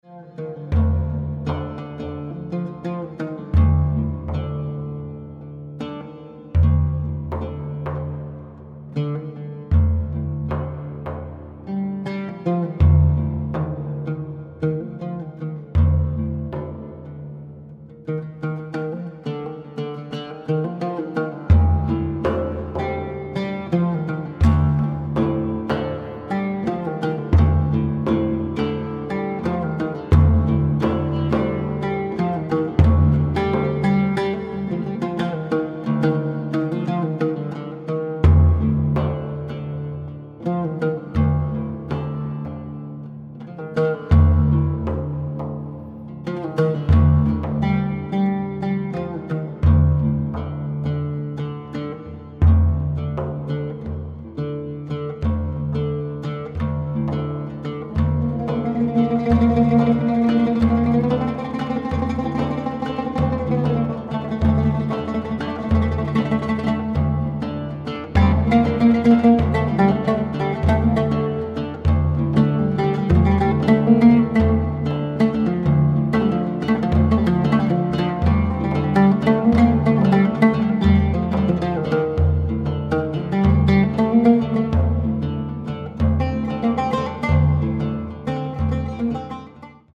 2x oud, frame drum